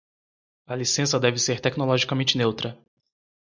Read more Meaning technologically Translations tecnológicamente Frequency 46k Pronounced as (IPA) /te.ki.noˌlɔ.ʒi.kaˈmẽ.t͡ʃi/ Etymology From tecnológico + -mente.